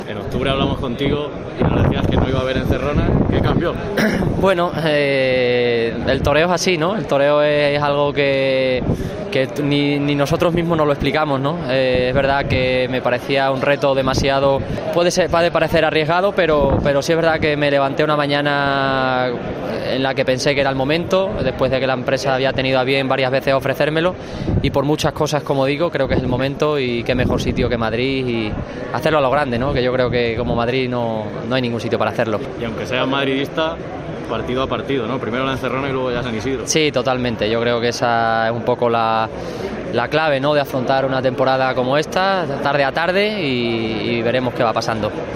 El Albero de COPE habló con los principales protagonistas que acudieron a la gala de presentación de la Feria de San Isidro 2022